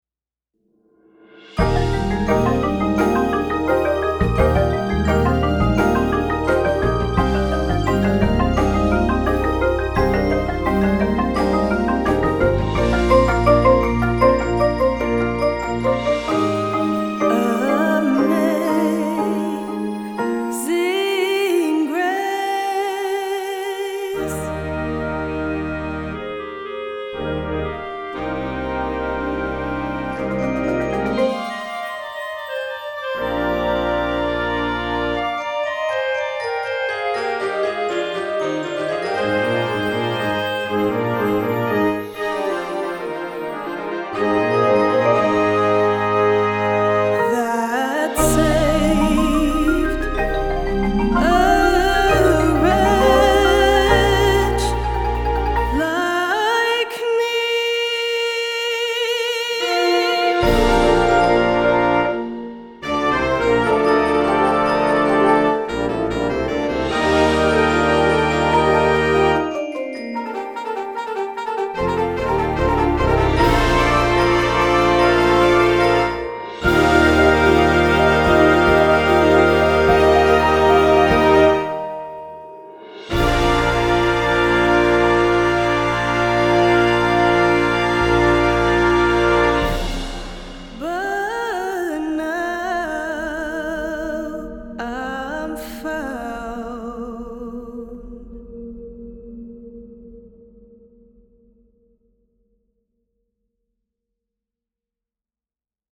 Marching Band Shows
Winds
Percussion
• 3 Marimbas
• Xylo/Bells
• 2 Synths